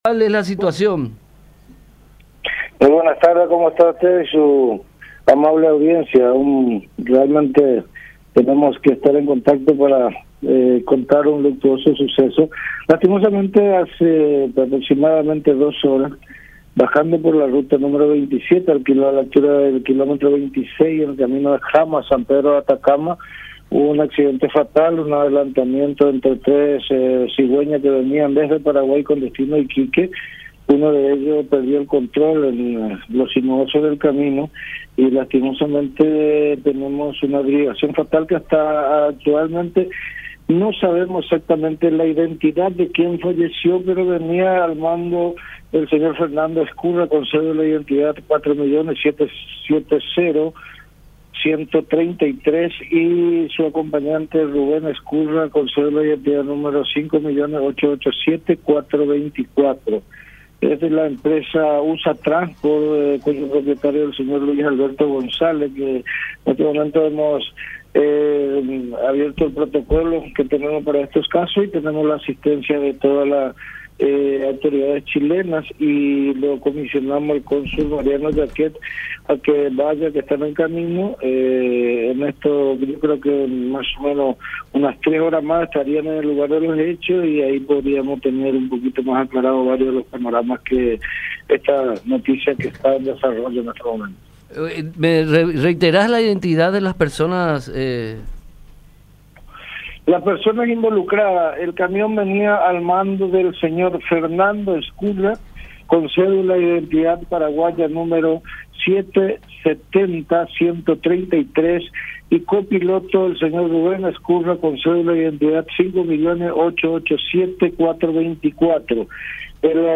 El cónsul paraguayo en Iquique, Alcides Albariño, detalló que el percance se produjo sobre la ruta 27, que une las localidades de Jama con San Pedro de Atacama, en una zona desértica. De acuerdo a los primeros informes, el accidente se produjo a raíz de un adelantamiento indebido, comentó en contacto con La Unión.